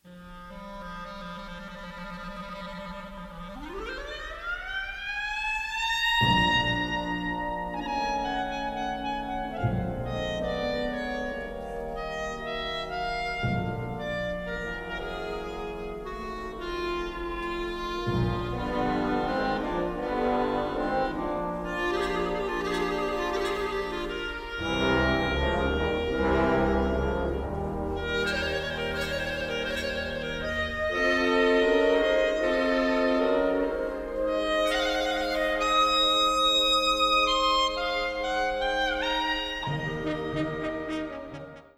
piano